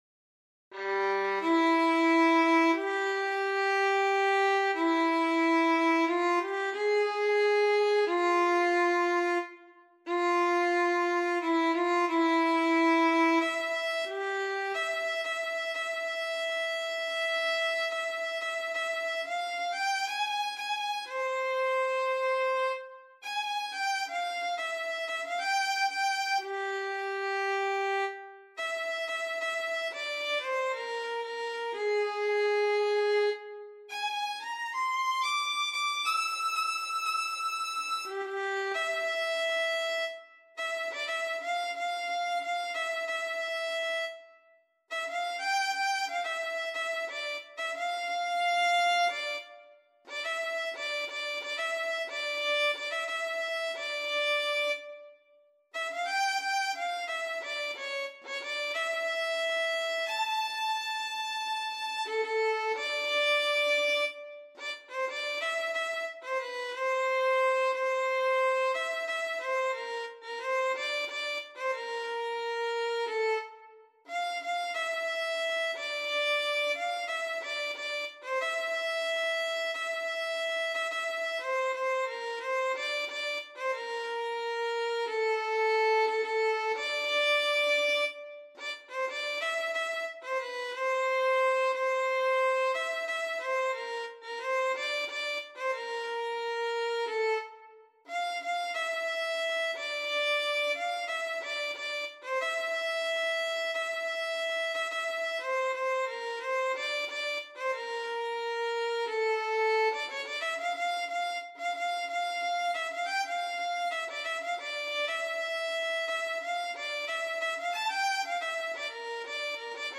ویولون
نت نویسی شده برای ویولن